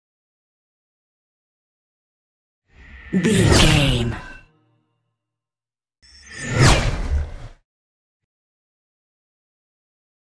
Scifi passby whoosh fast
Sound Effects
Fast
futuristic
whoosh